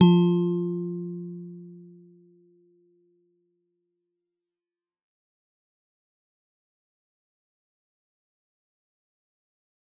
G_Musicbox-F3-f.wav